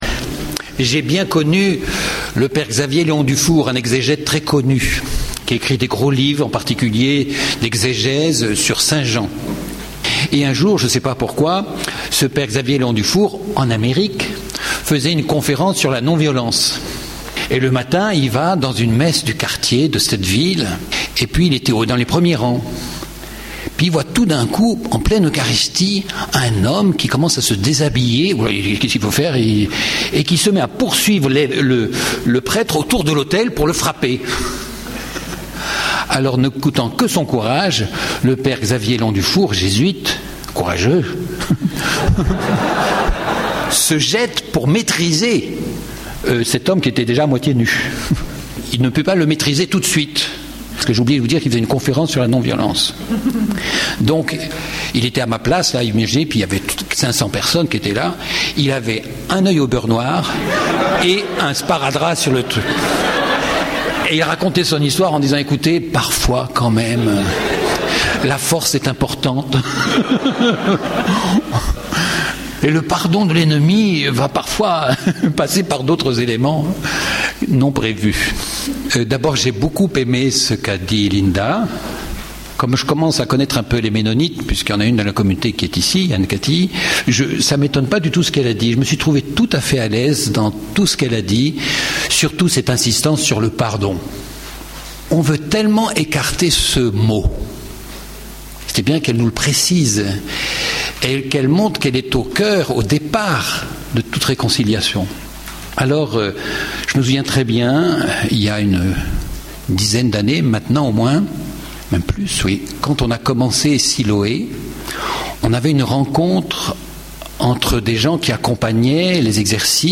Table ronde
Colloque Silo� 2009 - Non violence �vang�lique et conflits dans la vie professionnelle